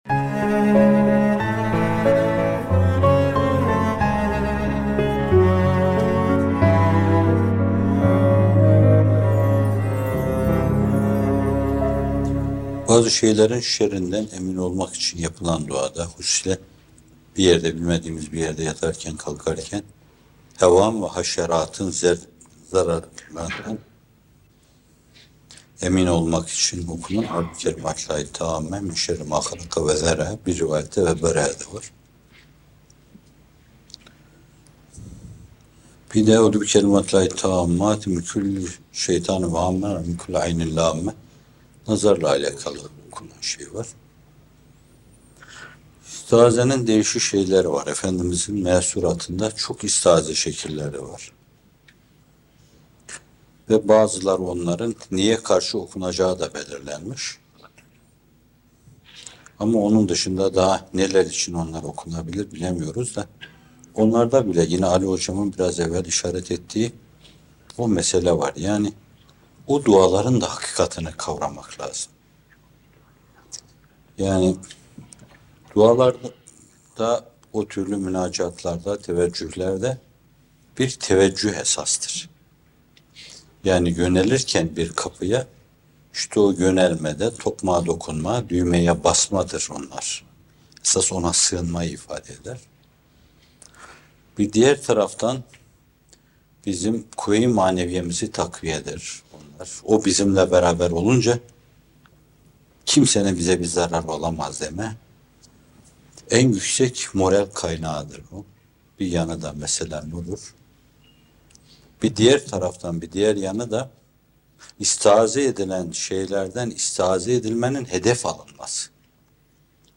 - Fethullah Gülen Hocaefendi'nin Sohbetleri